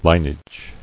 [lin·age]